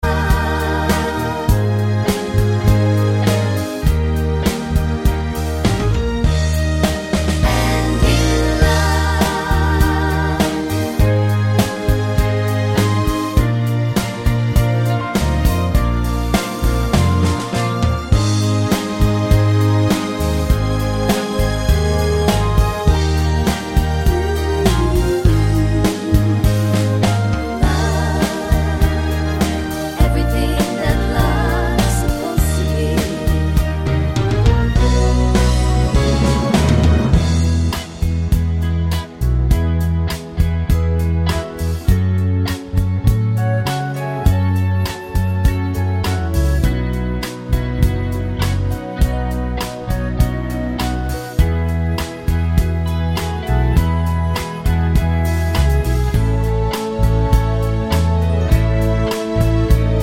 no Backing Vocals Crooners 3:01 Buy £1.50